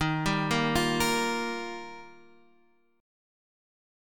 D#add9 chord